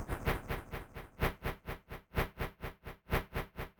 • rave sequence kashmir 3 - C - 126.wav
Royalty free samples, freshly ripped from a rompler, containing sounds of the early rave and hardcore from the 90′s. These can represent a great boost to your techno/hard techno/dance production.
rave_sequence_kashmir_3_-_C_-_126_xRQ.wav